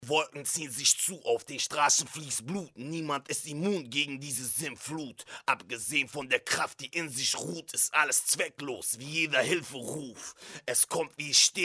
Avalon 737 U87 AI was läuft falsch ?
Hallo Leute trotz. Avalon sp737 und einem U87 ai , welches ich mir für Aufnahmen geliehen habe,finde ich das meine Aufnahme leider einfach beschissend klingt. Ich habe ganz normale Vocal einstellungen, das heisst es ist nichts verdreht. Kann es sein das die Avalon defekt ist ???? oder kann es sein das das MIC einfach mit der Stimme des Rappers nicht funktioniert ?
Die Aufnahme ist komplett Roh sowie unbearbeitet.